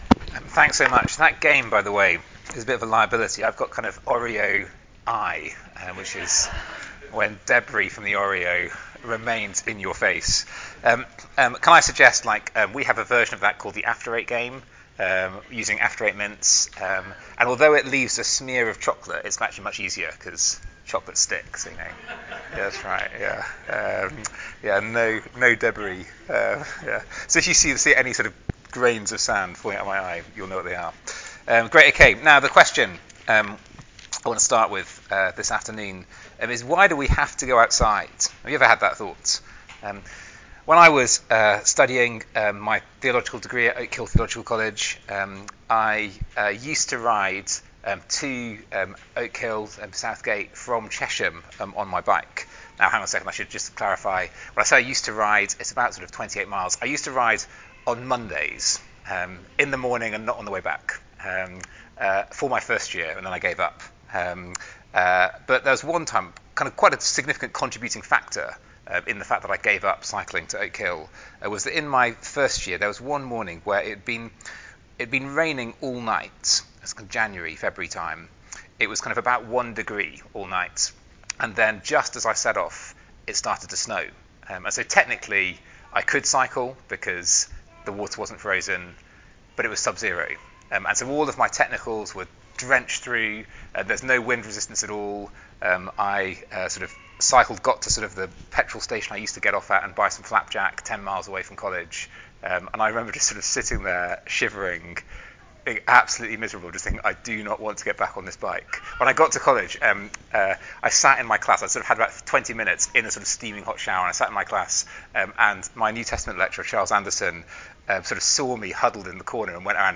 Passage: Isaiah 41-44 Service Type: Weekend Away « Are you ready to go outside?